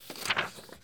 paper picked up 2.wav